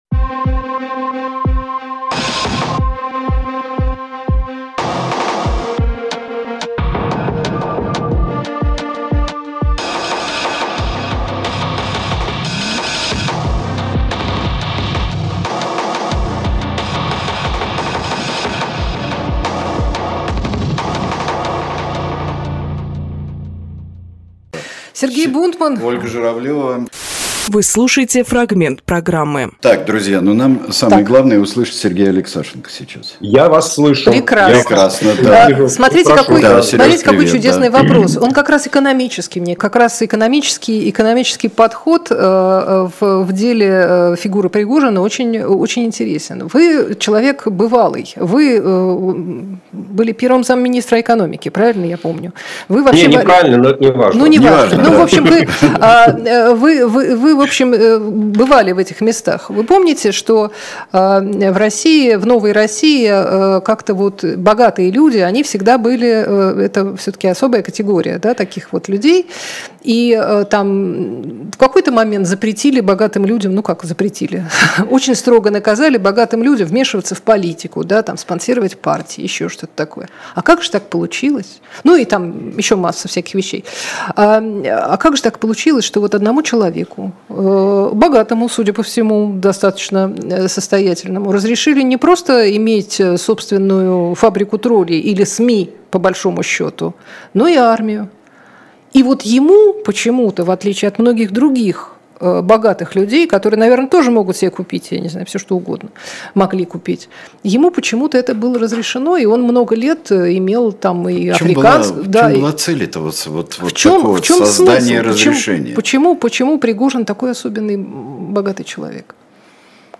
Сергей Алексашенкоэкономист
Фрагмент эфира от 24.06.23